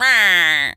pgs/Assets/Audio/Animal_Impersonations/duck_quack_hurt_02.wav at master
duck_quack_hurt_02.wav